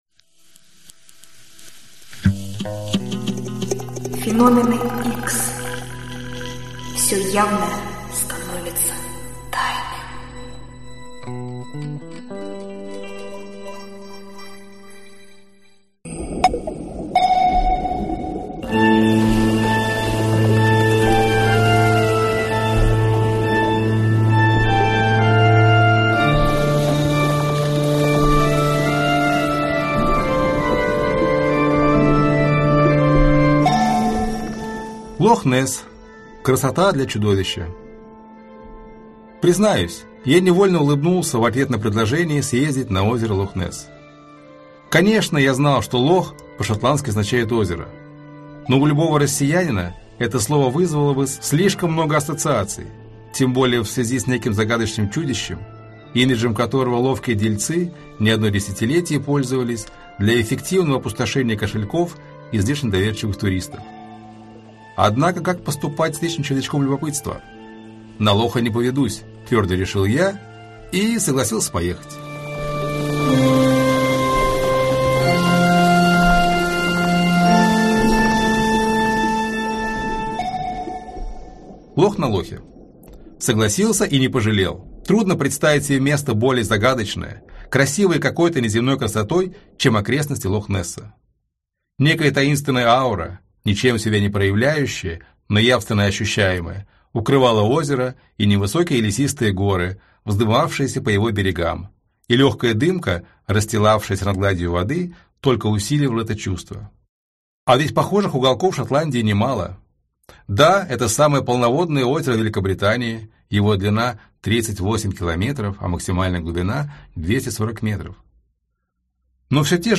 Аудиокнига Тайны Земли | Библиотека аудиокниг
Прослушать и бесплатно скачать фрагмент аудиокниги